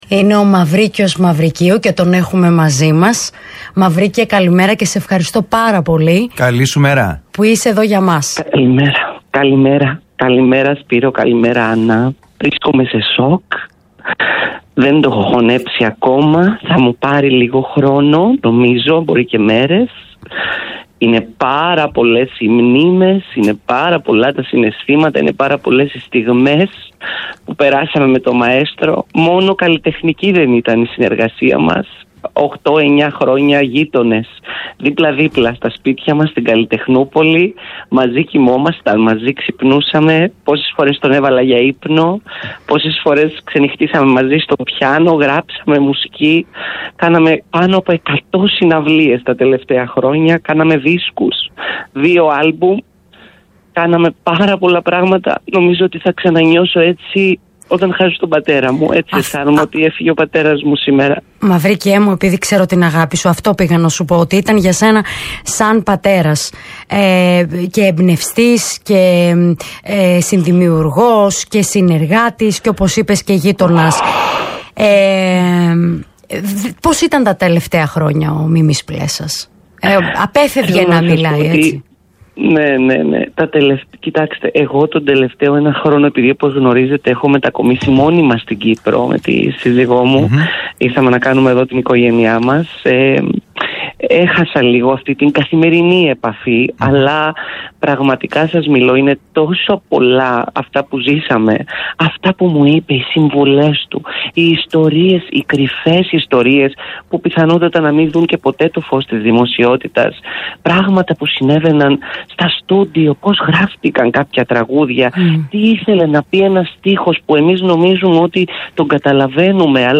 Ο γνωστός μουσικός μίλησε στο Talk Radio όπου «λύγισε» από συγκίνηση για όσα έζησε δίπλα στον σπουδαίο συνθέτη τον οποίο τον ένιωθε σαν πατέρα του, όπως αναφέρει χαρακτηριστικά για τον Μίμη Πλέσσα.